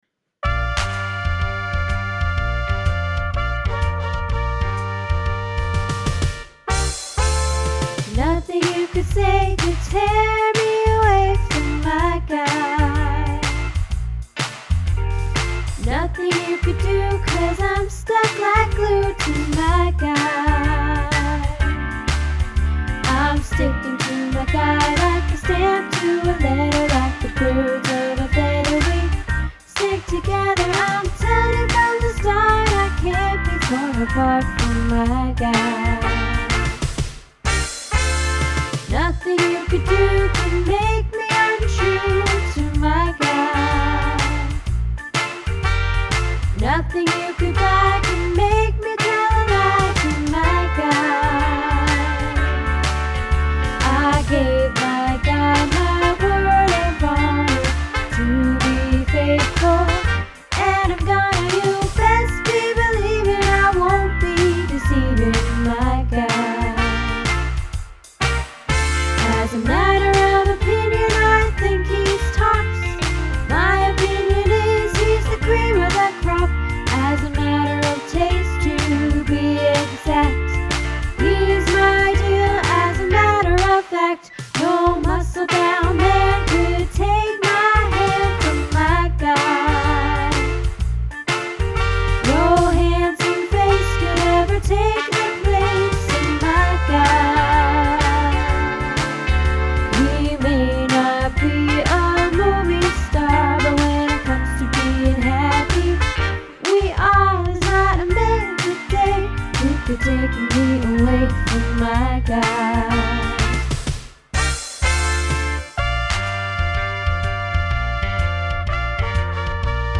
My Guy - Alto